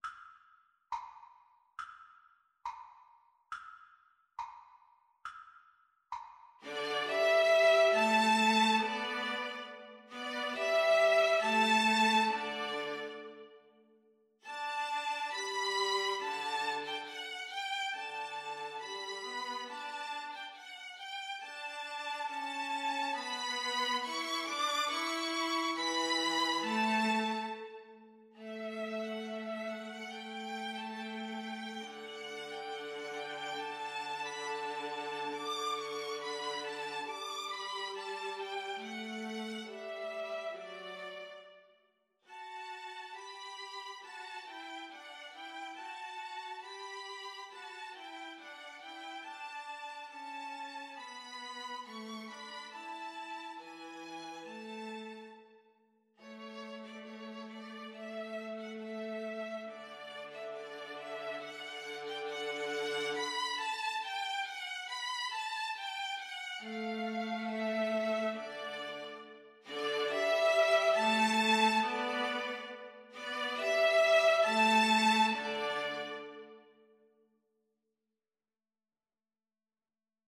D major (Sounding Pitch) (View more D major Music for 2-violins-viola )
Andantino = c.69 (View more music marked Andantino)